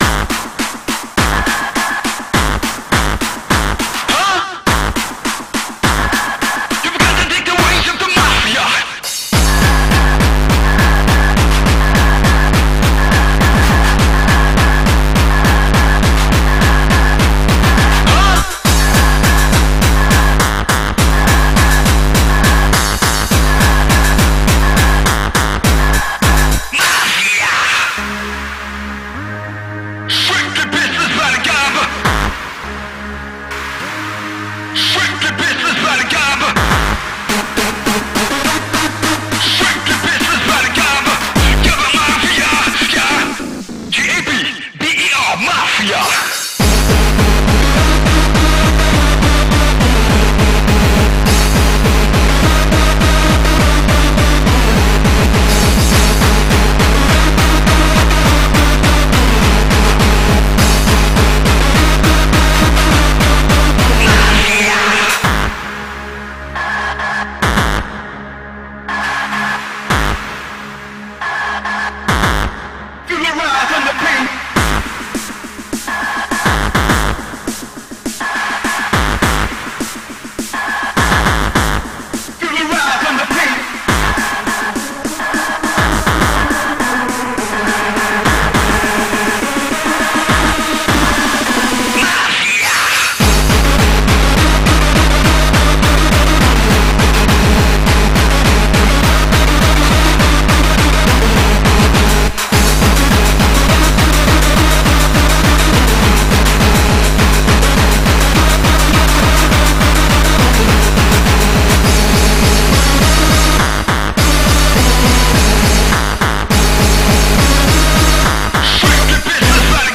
BPM206
Audio QualityPerfect (High Quality)
Comments[ROTTERDAM STYLE GABBER]